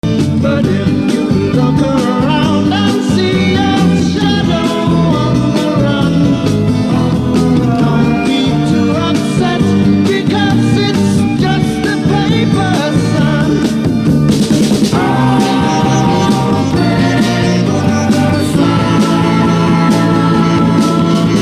it’s a bit more upbeat for a road trip